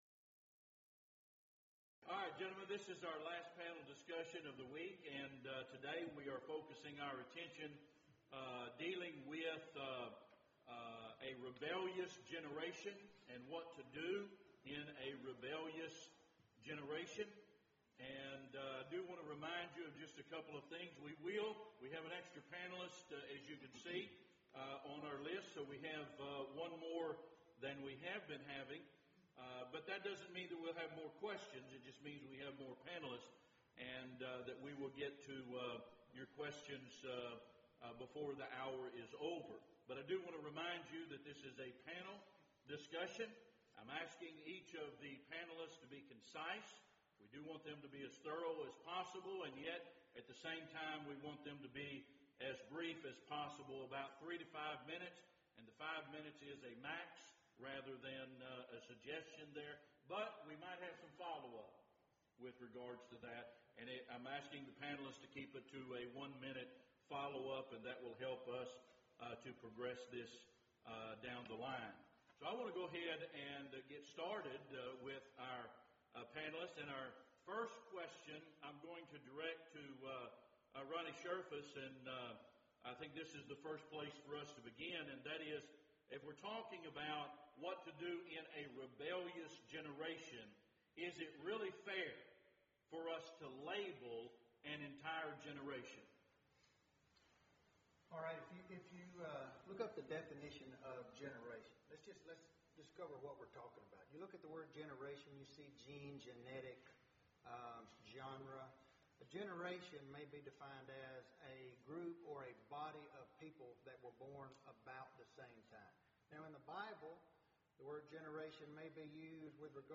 Speaker(s): Various
Event: 4th Annual Men's Development Conference